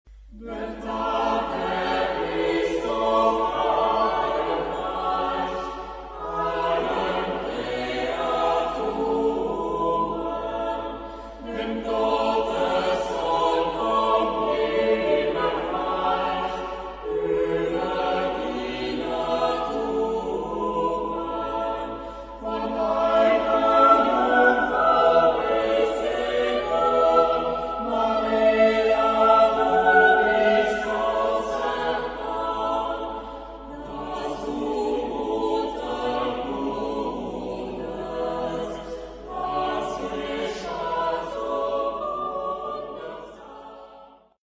Epoque: 16th century
Genre-Style-Form: Sacred ; Christmas song ; Renaissance ; Canticle
Type of Choir: SATB  (4 mixed voices )
Tonality: A major